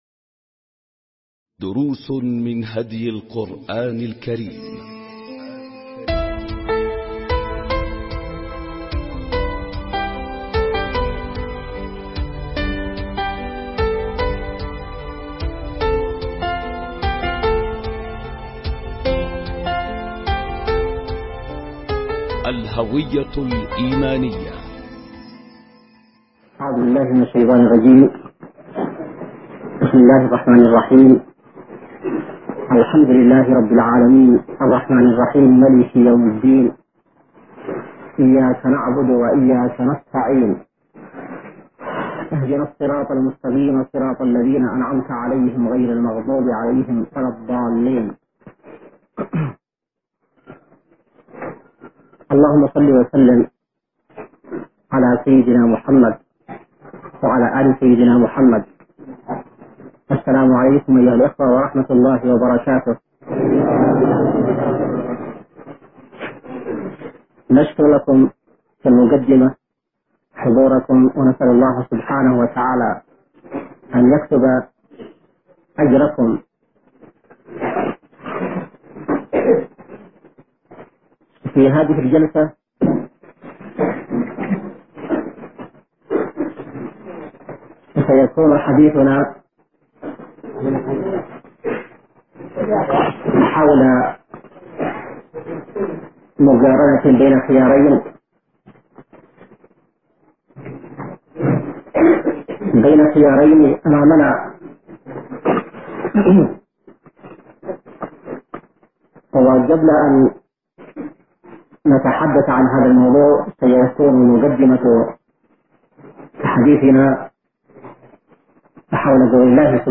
🟢 دروس من هدي القرآن الكريم 🔹الهوية الإيمانية🔹 ملزمة الأسبوع | اليوم الأول ألقاها السيد / حسين بدرالدين الحوثي بتاريخ 31/1/2002م | اليمن – صعدة | مؤسسة الشهيد زيد علي مصلح